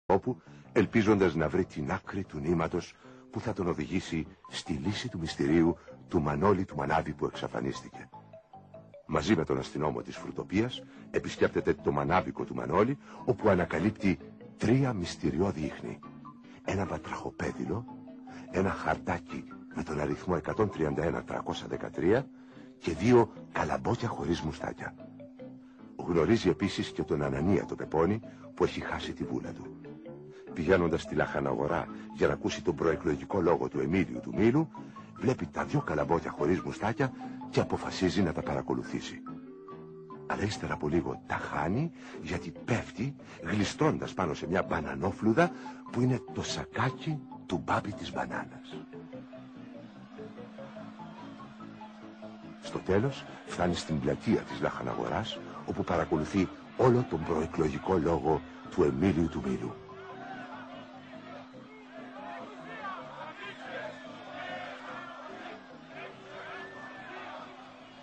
Πηγή: Τηλεοπτική σειρά (παραμύθι)